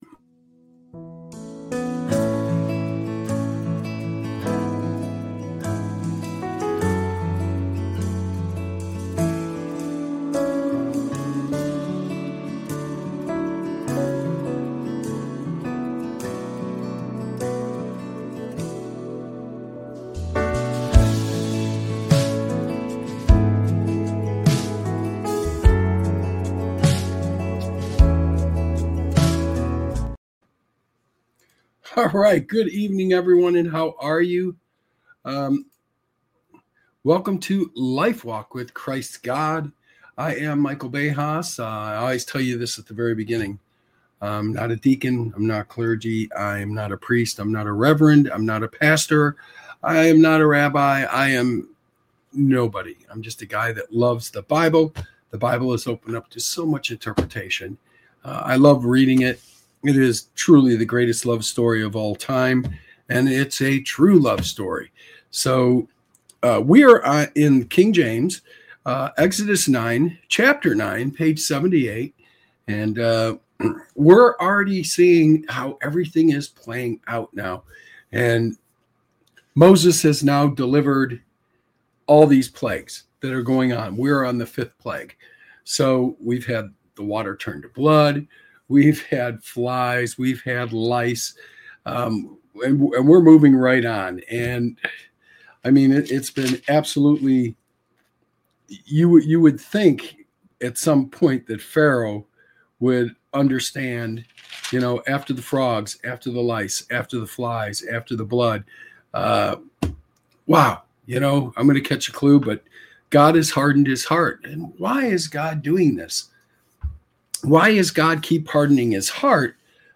This show offers a reading into the teachings of Jesus Christ, providing insights into the Bible.